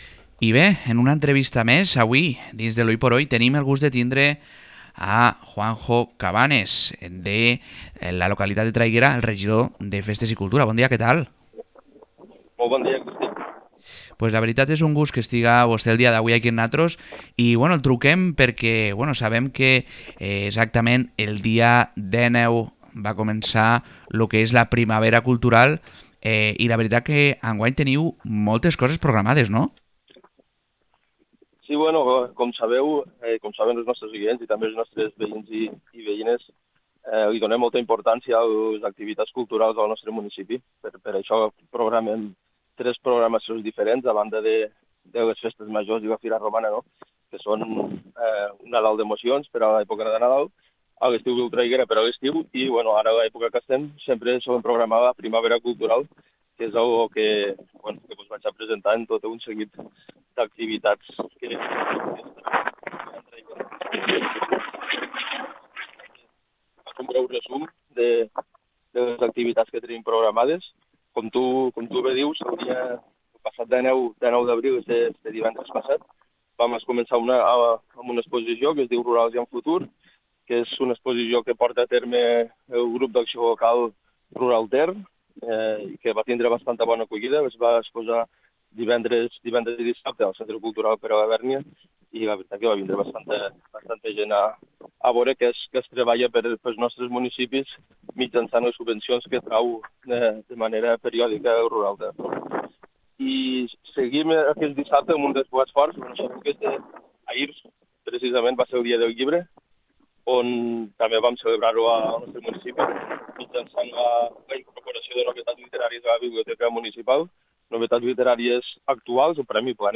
Podcast | Entrevista Juanjo Cabanes regidor de cultura i festes de Traiguera sobre la primavera cultural